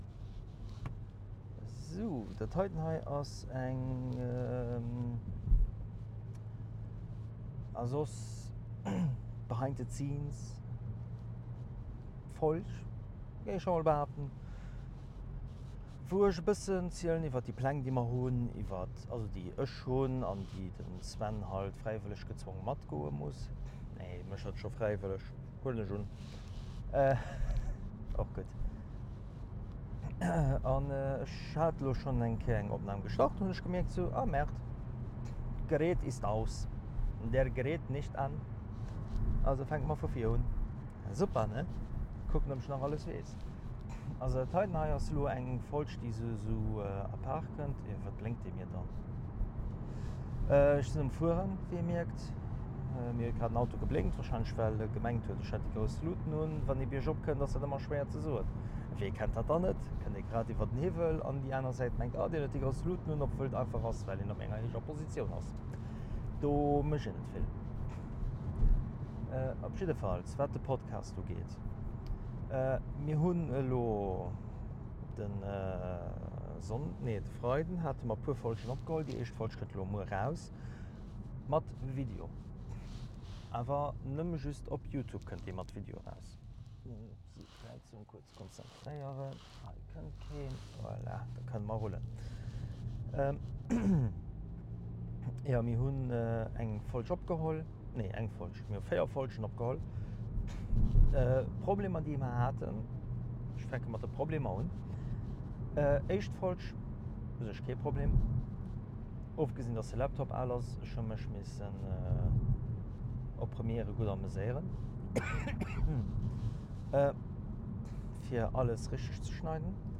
z001 A Soss behind the scenes a méi (oppassen se ass am Auto opgeholl ginn)